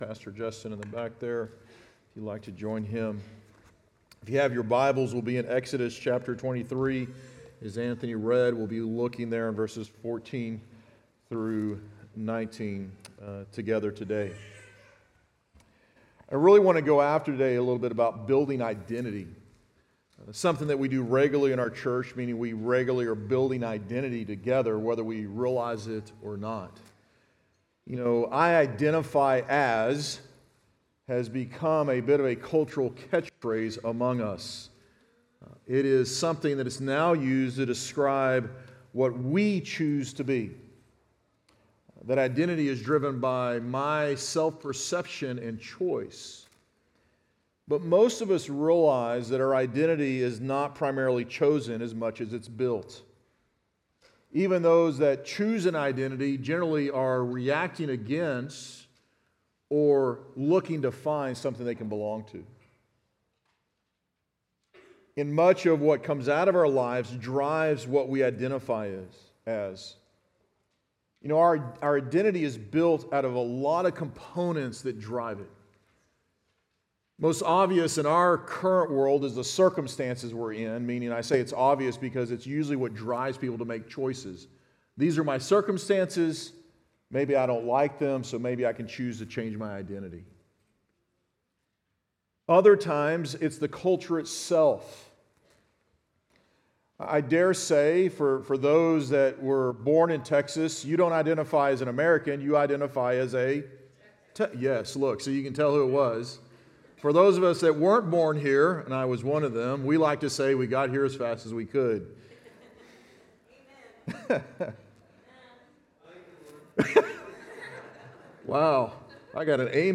Sermon Audio Only